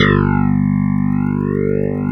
Index of /90_sSampleCDs/USB Soundscan vol.09 - Keyboards Old School [AKAI] 1CD/Partition B/04-CLAVINET3
CLAVI3  G1.wav